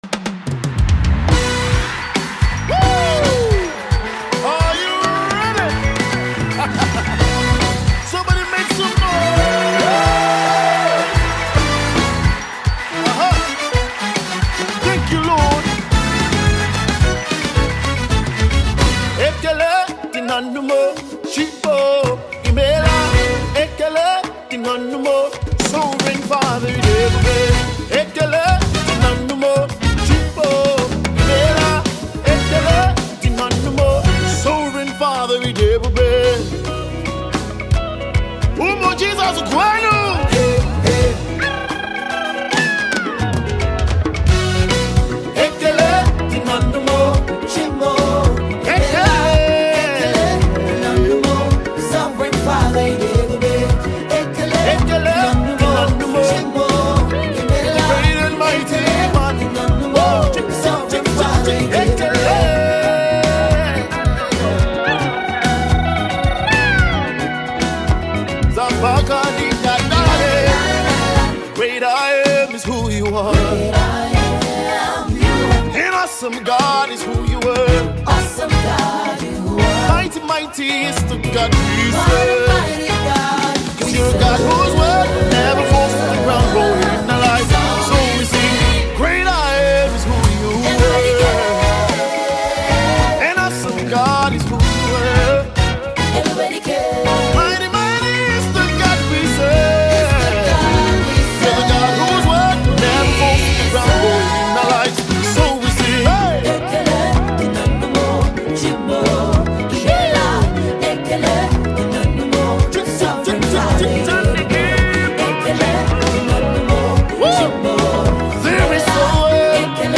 ballad live studio tune